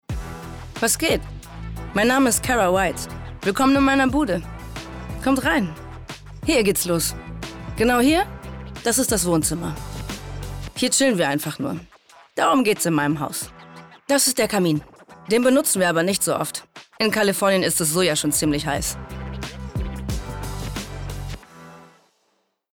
dunkel, sonor, souverän, markant
Mittel minus (25-45)
Norddeutsch
Voice Over MTV Cribs
Doku